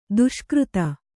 ♪ duṣkřta